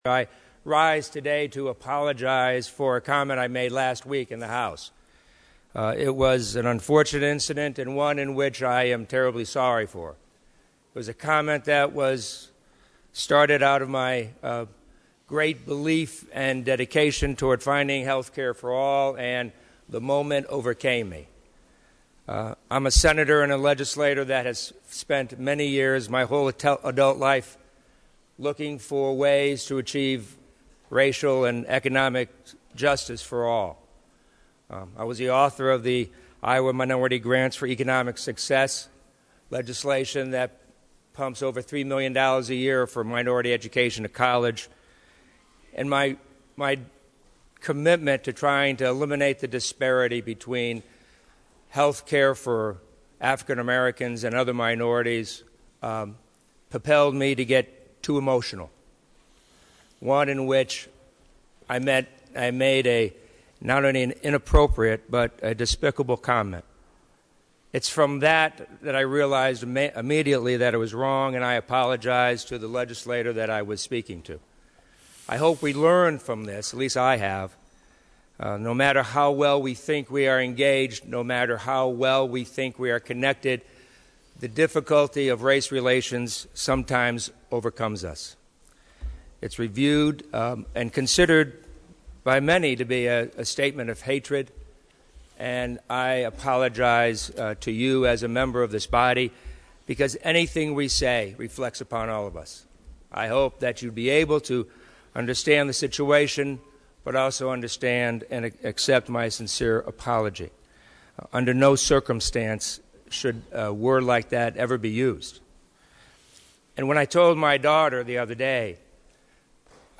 Shortly after the senate convened today, Hatch spoke publicly about the incident.